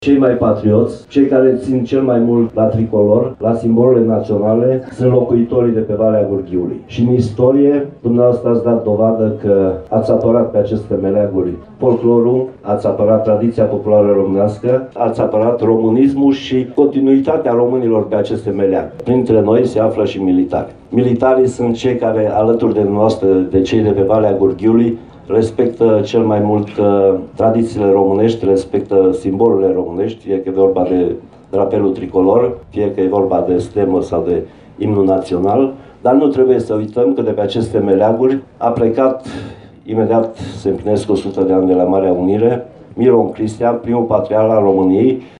Evenimentul a ajuns la cea de-a zecea ediție, și a reunit la sfârșit de săptămână câteva mii de oameni pe Platoul ”La Fâncel” din comuna Ibănești.
A fost prezent și ministrul Apărării Naționale, Mircea Dușa, care lăudat patriotismul local și i-a salutat pe soldații prezenți la festival.